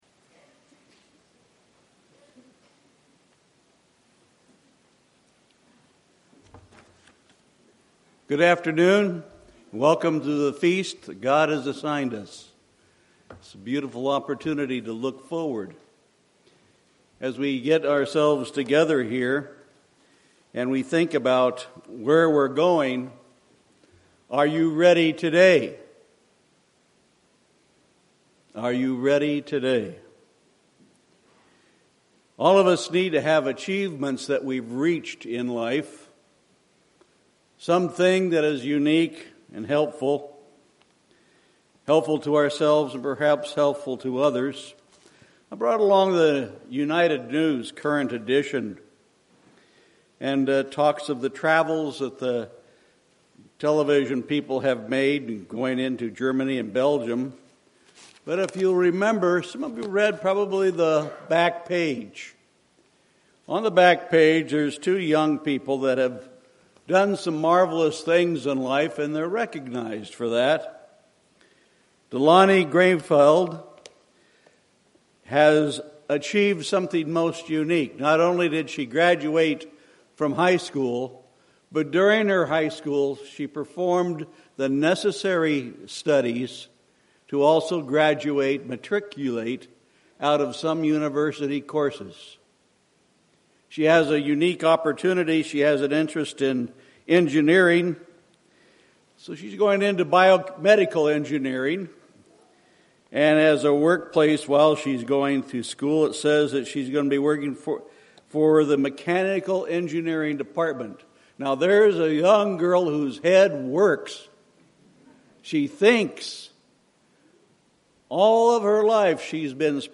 This sermon was given at the Phoenix, Arizona 2017 Feast site.